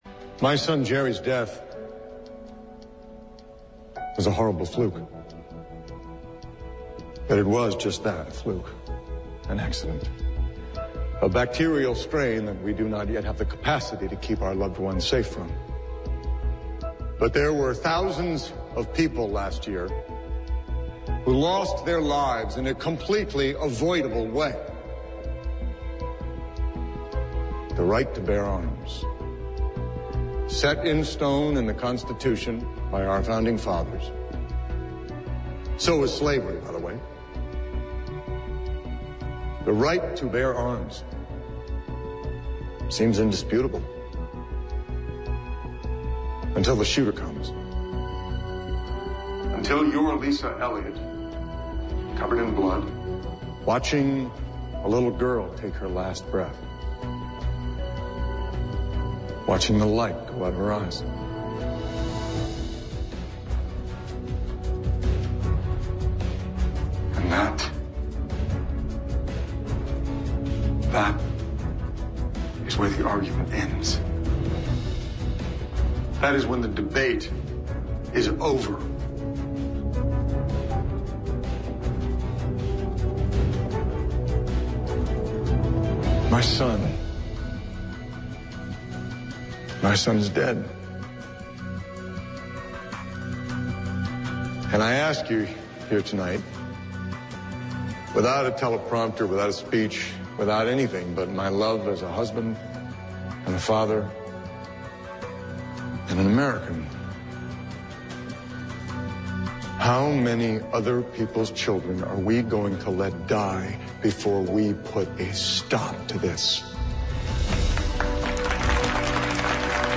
Grant,” played by Tony Goldwyn (IMDB page), recalled how his teen son died of a “fluke” infection (not true, that’s one of many of Scandal’s wild conspiracies), but “there were thousands of people last year who lost their lives in a completely avoidable way.”
President Grant, in the House chamber, during the October 2 episode of Scandal, delivering words which generated hearty cheers and applause (the woman seen exiting the Capitol, in the video, is star “Olivia Pope,” a political fixer played by Kerry Washington, who had urged “Grant” to insert the plea into his prepared text):